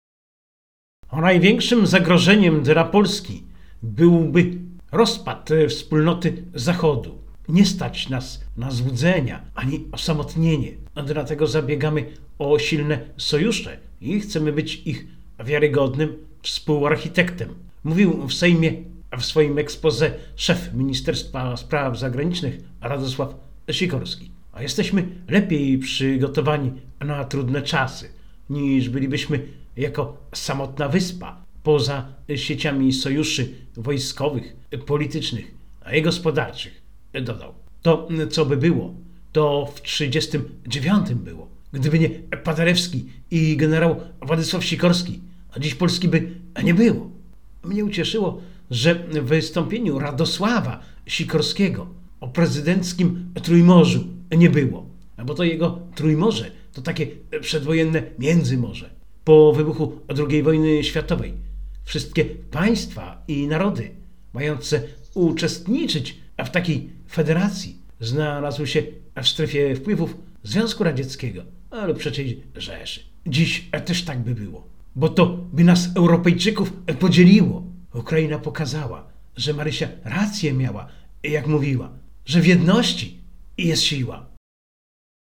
23.04.Wystapienie-Sikorskiego.mp3